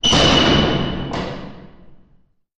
Bolts X 7, Various; Reverberant Interioor